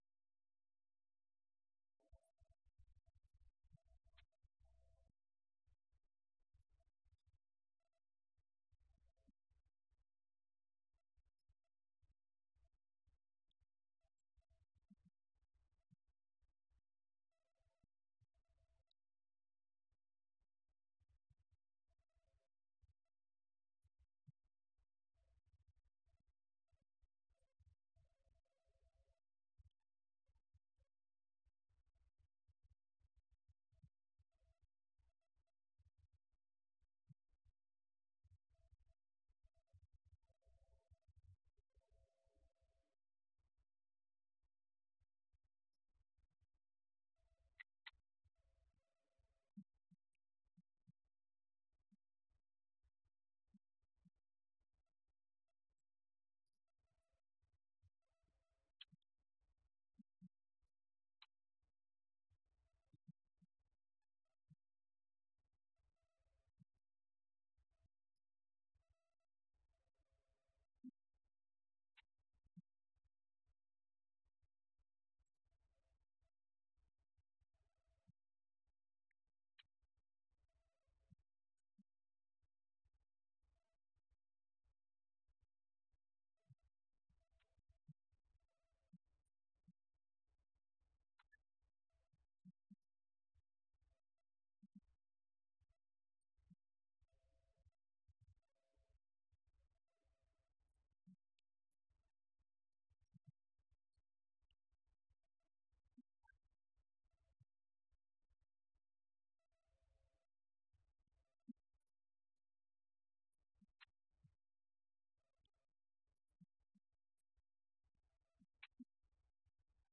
Event: 17th Annual Schertz Lectures Theme/Title: Studies in Job